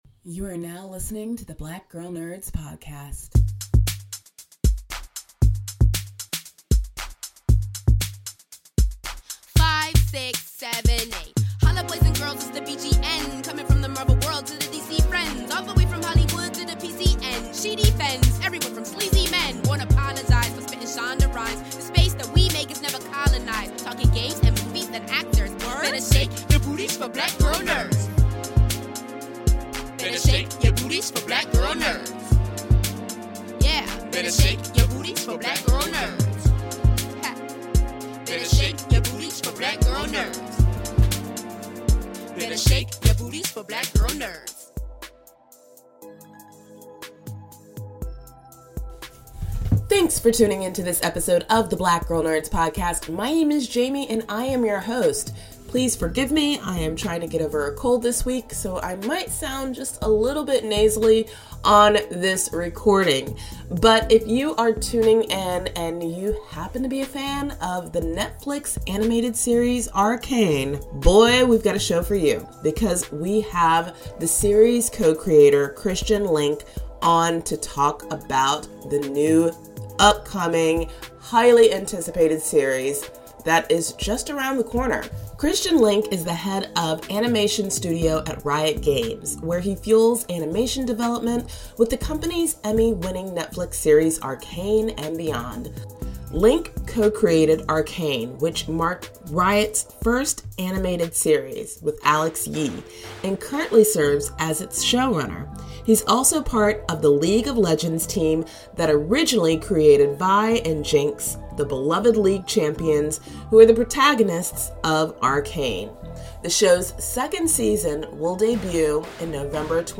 In this week's episode of the Black Girl Nerds podcast, we welcome co-creator and showrunner of the Netflix series 'Arcane' Christian Linke.